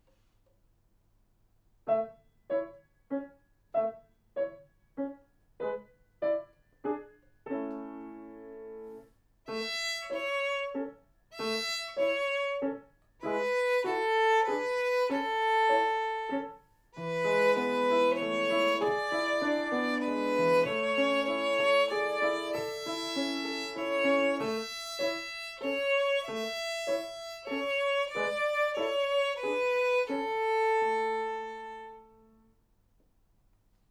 ご自宅での練習用に録音しました。